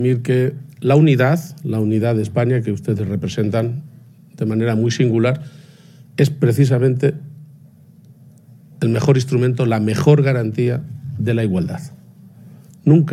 Presidente Jueves, 19 Diciembre 2024 - 12:45pm La unidad de España es la mejor garantía de la igualdad" ha dicho hoy en Cuenca el presidente de Castilla-La Mancha en el marco de la inauguración del nuevo hospital de Cuenca que ha corrido a cargo de los Reyes de España. garcia-page_unidad_igualdad.mp3 Descargar: Descargar